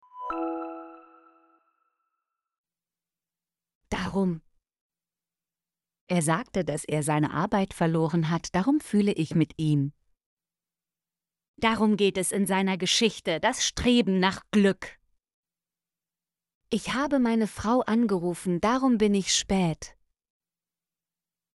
darum - Example Sentences & Pronunciation, German Frequency List